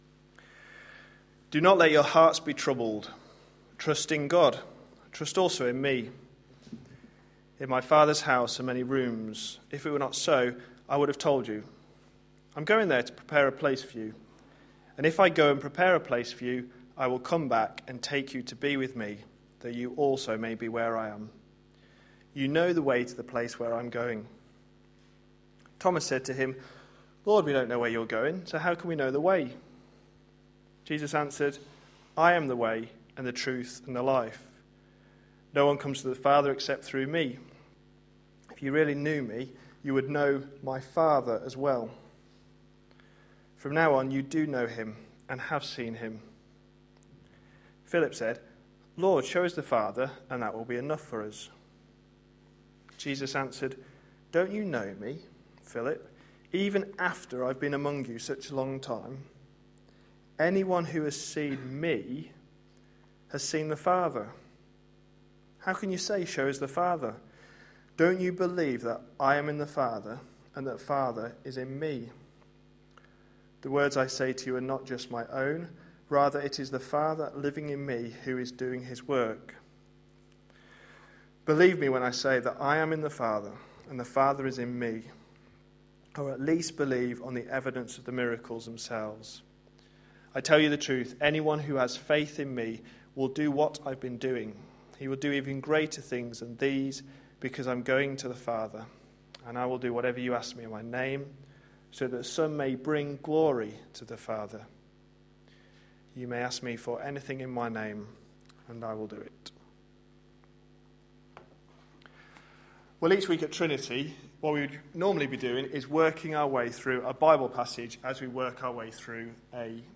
A sermon preached on 16th December, 2012.